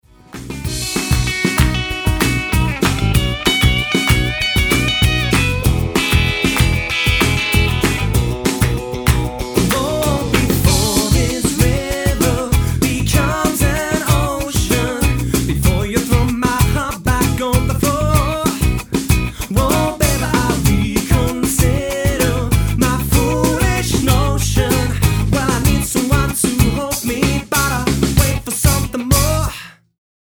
Acoustic Cover mit mehrstimmigem Gesang?